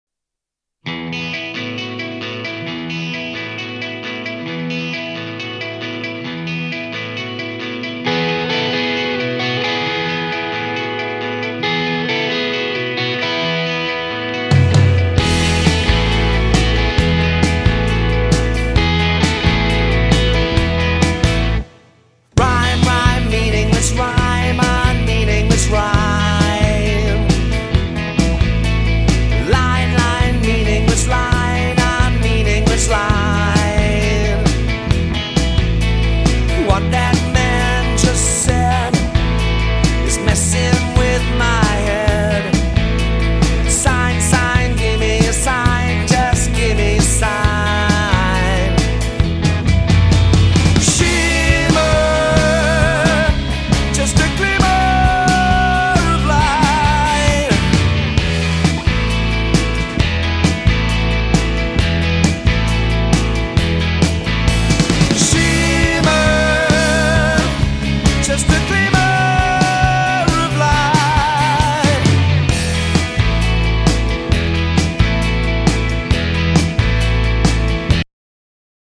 abotsa, gitarrak eta teklatuak
baxua
bateria, perkusioak eta abotsak